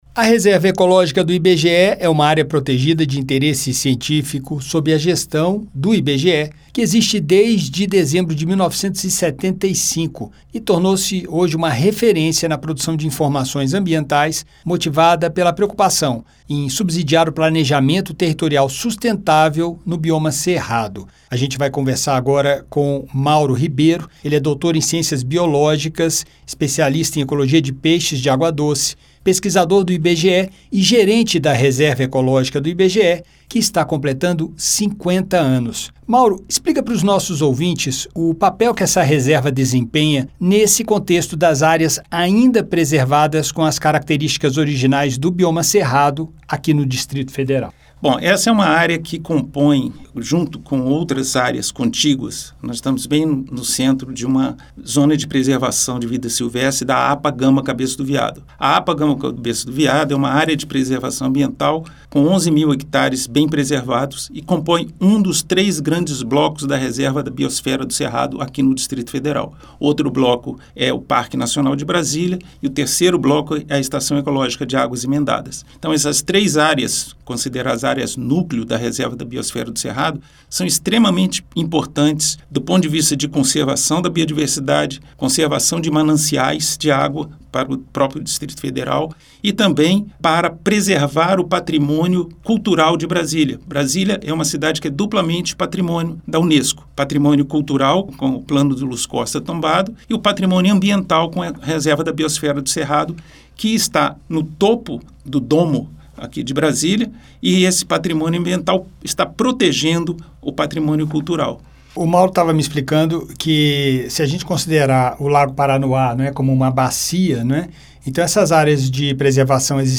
Acompanhe a entrevista e saiba como acessar informações ou agendar uma visita.